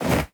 CLOTHING_MATERIAL_MOVEMENT_01.wav